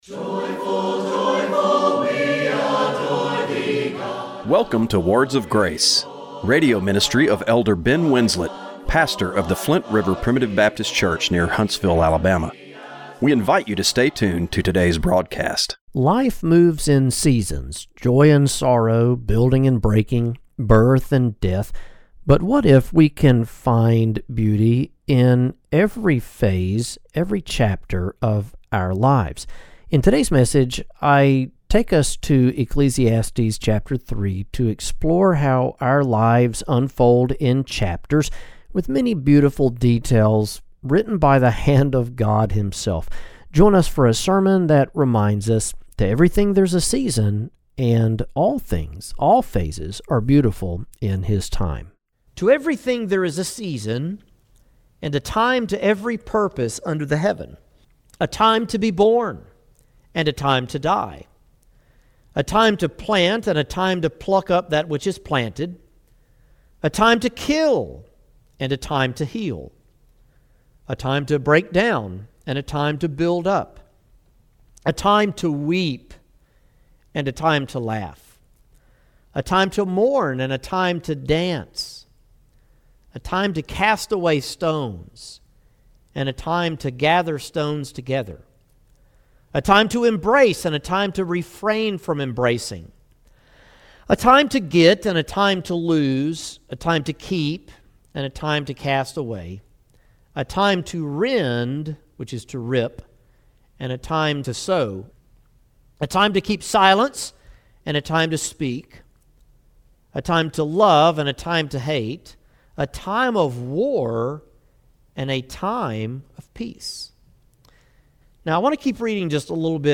📖 Scripture references: Ecclesiastes 3:1–11, Psalm 139, Colossians 1:16, 2 Chronicles 16:9 Radio broadcast for August 10, 2025.